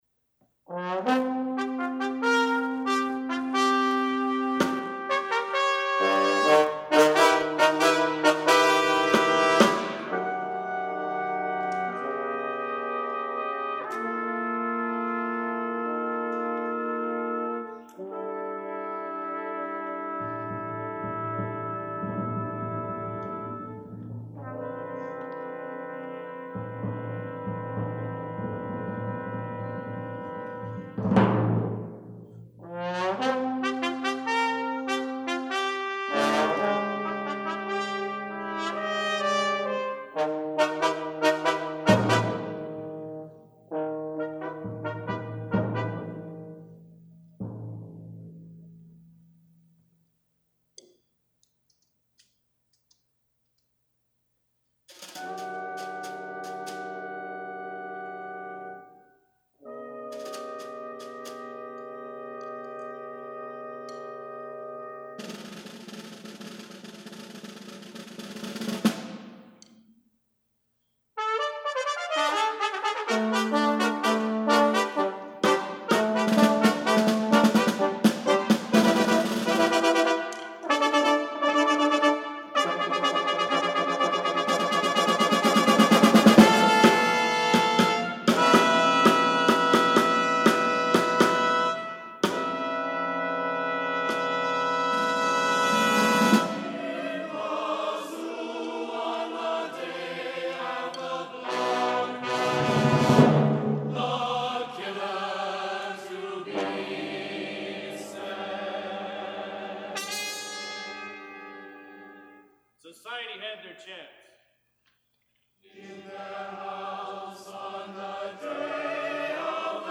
(A piece for chorus, brass and percussion)
I recently found a cassette tape of a performance — the only other performance — that the West Virginia University Chorus and Orchestra recorded decades later when I was on the WVU Theatre faculty.